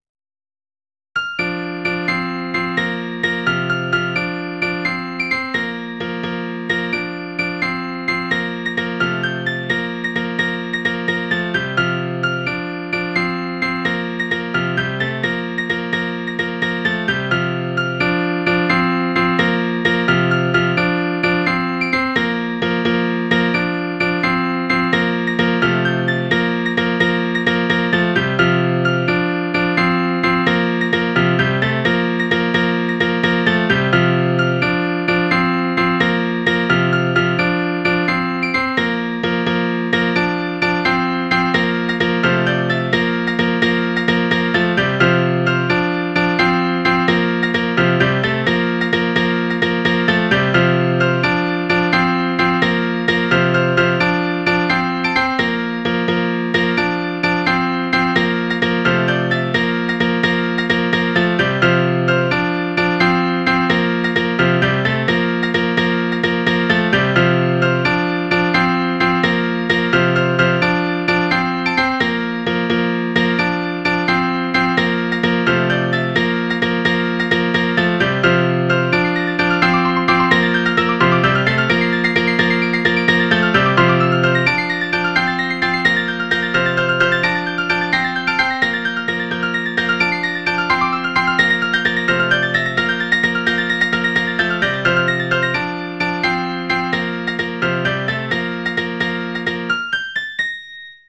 Midi File, Lyrics and Information to Andrew Barton
This is based on the single line melody in Child Vol. 5.